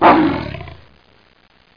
LIONROAR.mp3